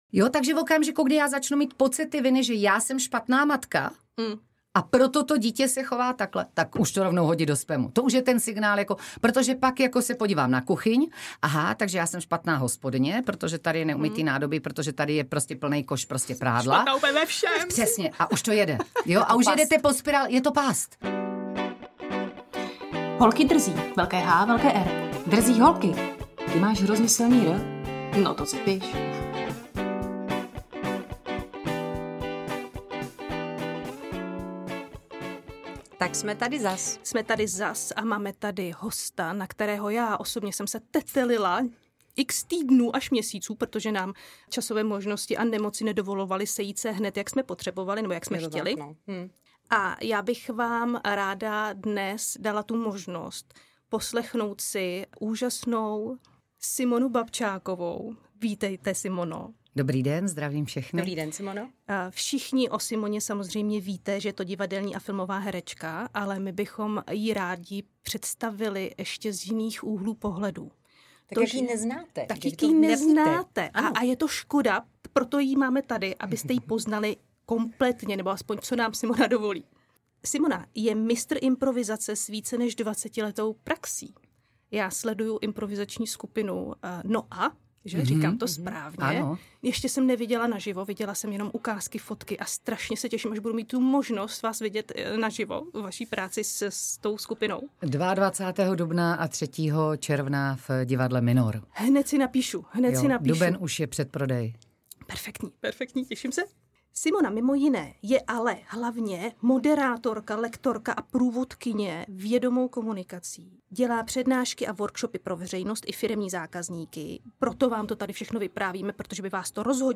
Na toto téma jsme si pozvaly vzácného hosta.
Simona s námi sdílí svůj příběh. Jak sama vyhořela, jak si hlídá, aby se to nestalo znovu, na jaké příznaky si dát fakt majzla. To vše bez obalu, bez patosu, zato s pořádnou dávkou vtipu.